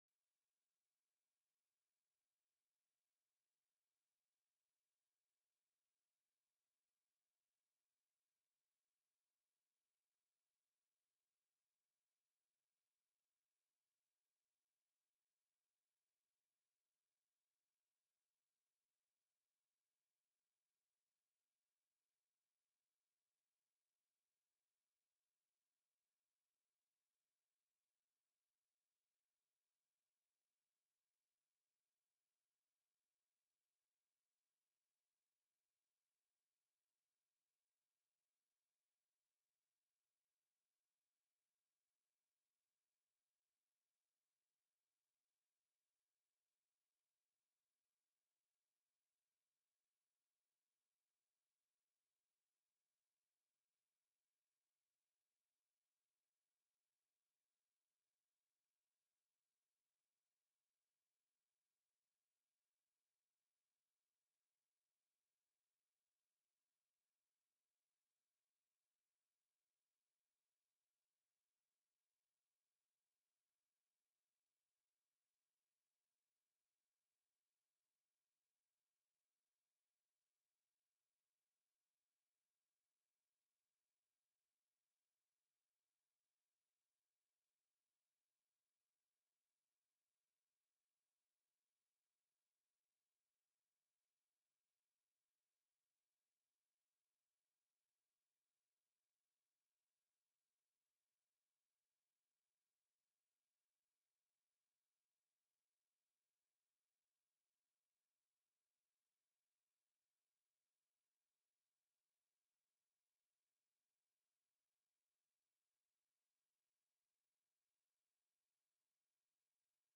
The audio recordings are captured by our records offices as the official record of the meeting and will have more accurate timestamps.
HB 263 APPROP: OPERATING BUDGET;AMEND;SUPP TELECONFERENCED